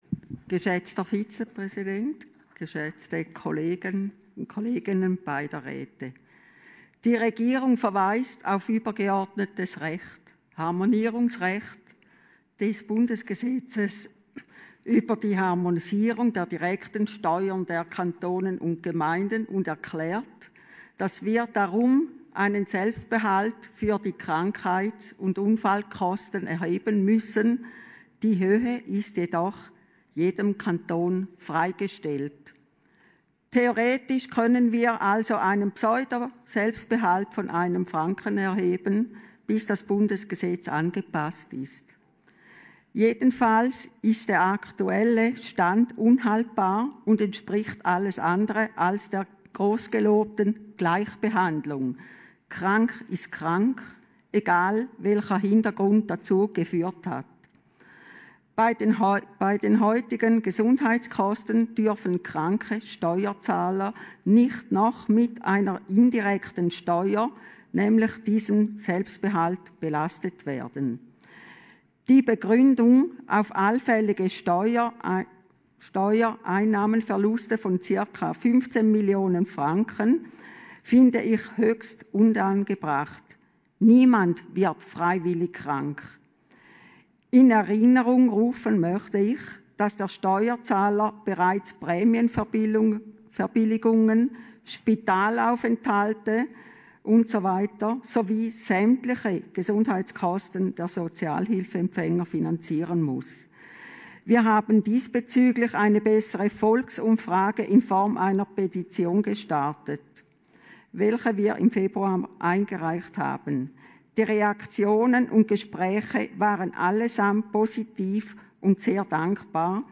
18.5.2020Wortmeldung
Session des Kantonsrates vom 18. bis 20. Mai 2020, Aufräumsession